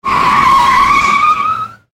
Car Brake Screeching Sound Effect
Loud tire screech from a car at high speed. The car brakes suddenly, and you can hear the tires screeching on the asphalt.
Car-braking-with-tire-screeching-sound-effect.mp3